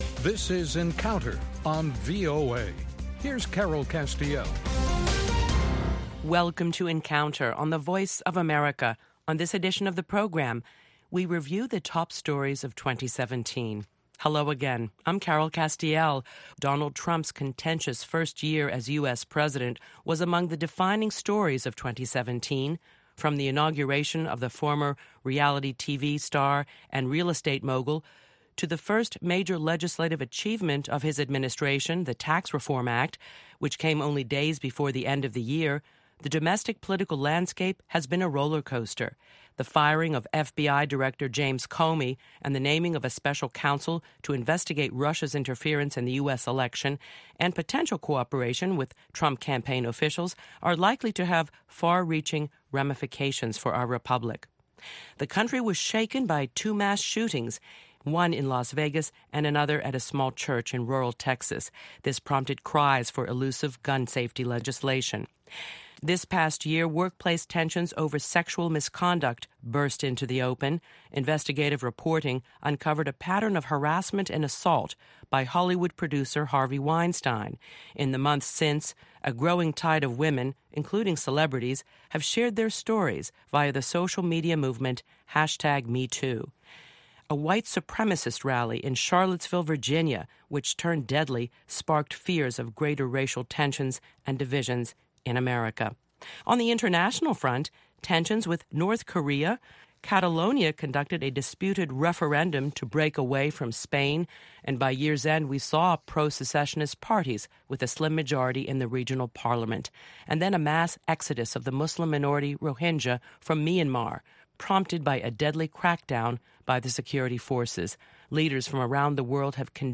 Two distinguished journalists